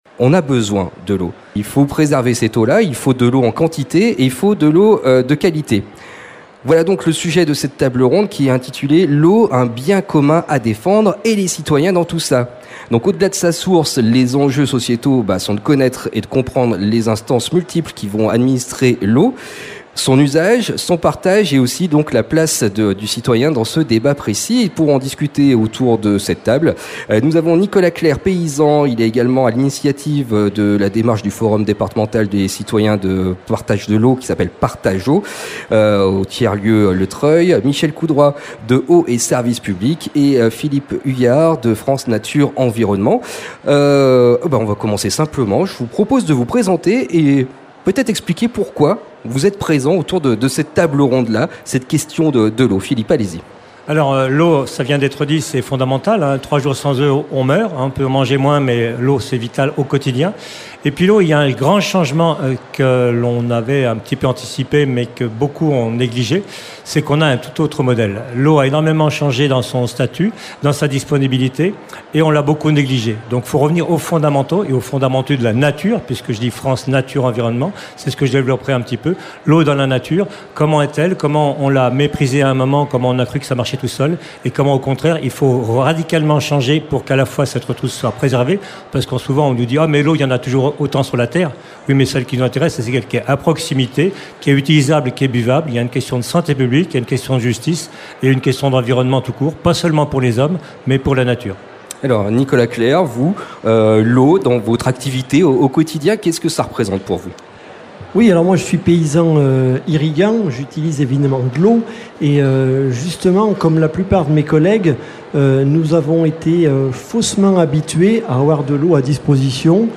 Retrouvez ici toutes les tables rondes, interviews et conférences réalisées à l’occasion du Rendez-vous de la Transition Écologique Tatou Juste 2023, avec comme depuis 15 ans aujourd’hui, notre collaboration Radio Ondaine, Radio Dio!!!